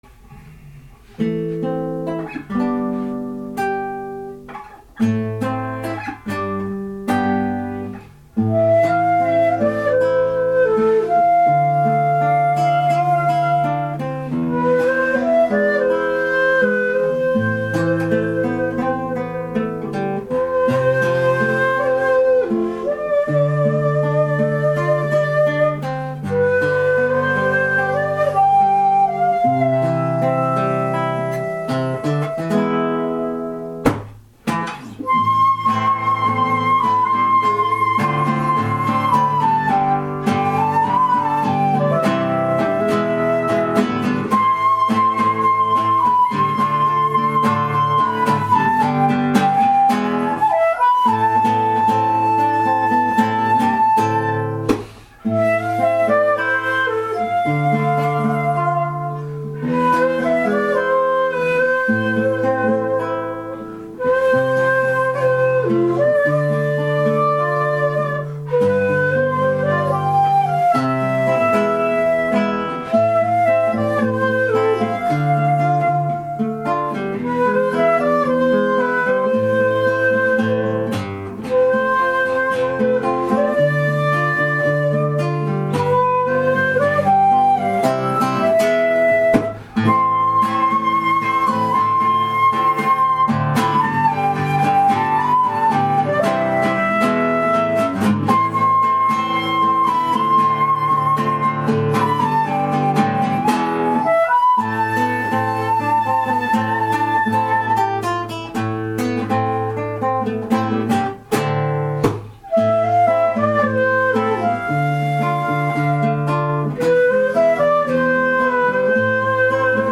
尺八
・尺八は「心のこもった」感じがする
・尺八の音は何か「人の声」に近い感じがする